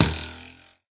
bowhit3.ogg